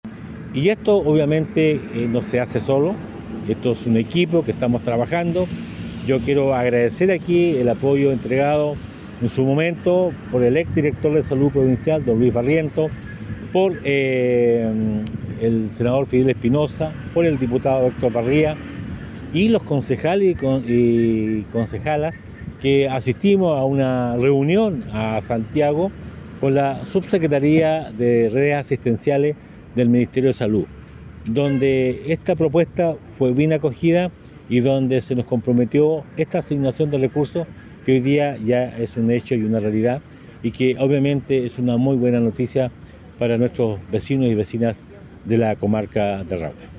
El Alcalde Carrillo, destacó el trabajo que ha realizado todo el equipo para llevar a cabo esta iniciativa, además del apoyo de concejales y parlamentarios.